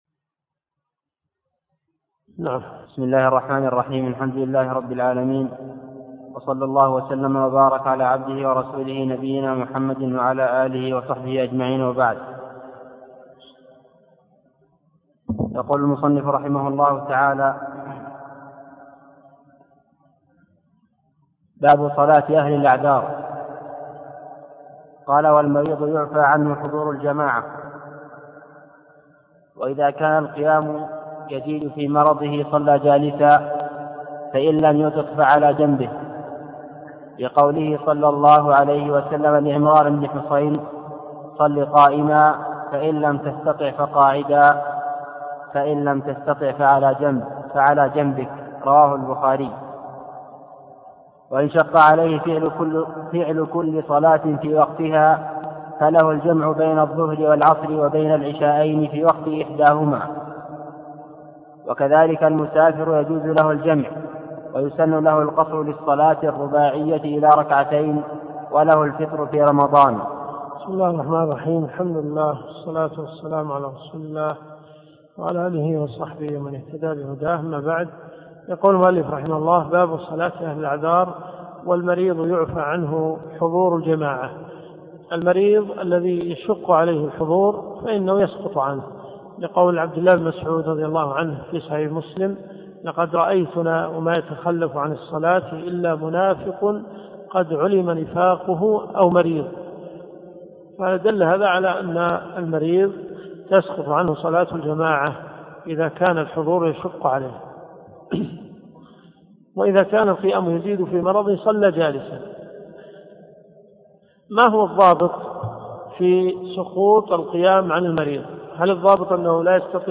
دروس صوتيه
منهج السالكين . كتاب الصلاة . من ص 32 باب صلاة أهل الاعذار -إلى- ص 35 قوله الله أكبر ولله الحمد . المدينة المنورة . جامع البلوي